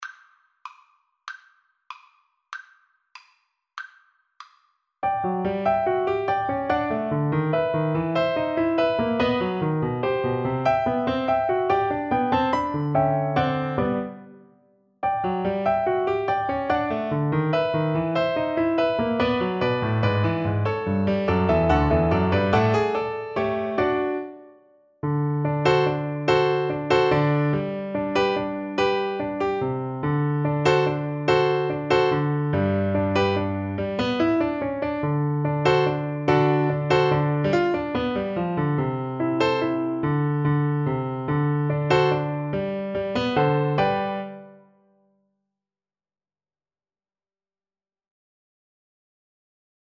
G major (Sounding Pitch) (View more G major Music for Viola )
. = 96 Allegro (View more music marked Allegro)
Classical (View more Classical Viola Music)